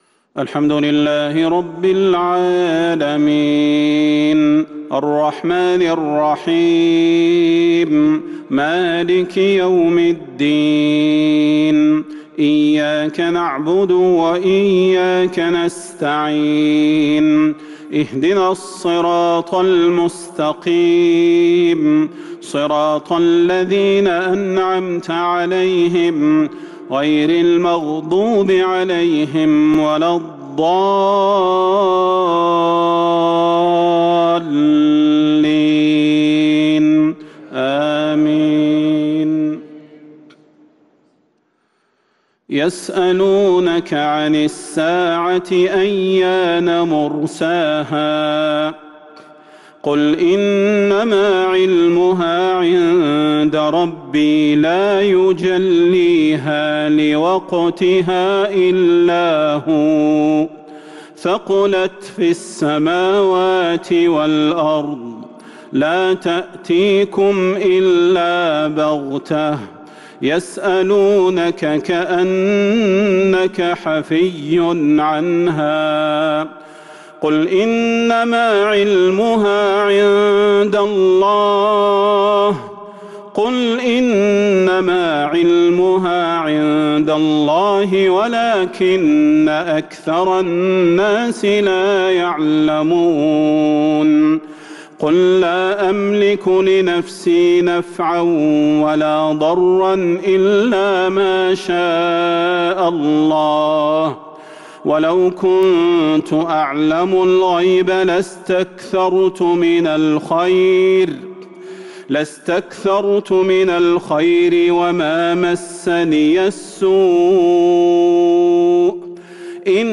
عشاء الأحد 6-6-1443هـ من سورة الأعراف | isha prayer from Surat Al-Araf 9-1-2022 > 1443 🕌 > الفروض - تلاوات الحرمين